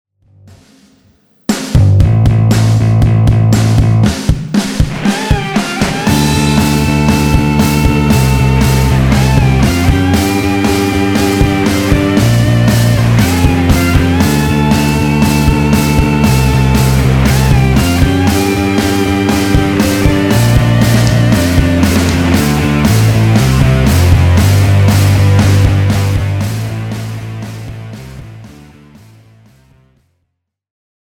60s Acid Rock